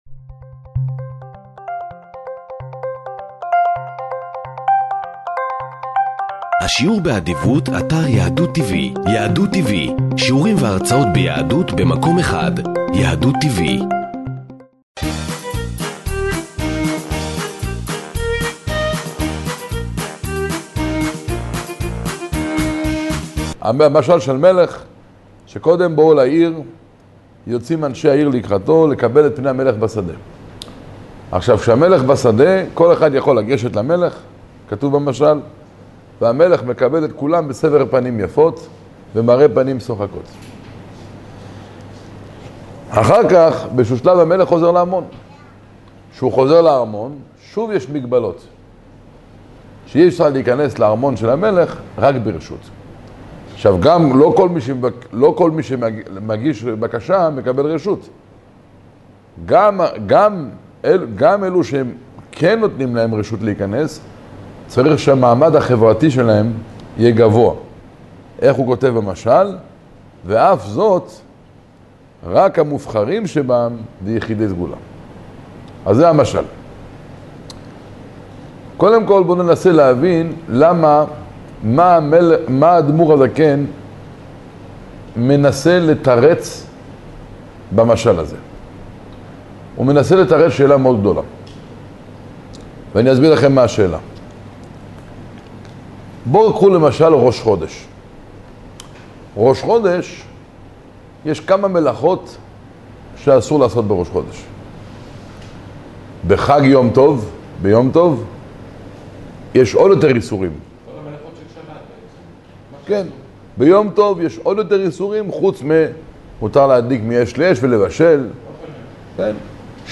המלך בשדה ● שיעור תניא